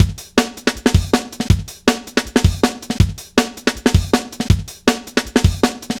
Index of /90_sSampleCDs/Zero-G - Total Drum Bass/Drumloops - 1/track 10 (160bpm)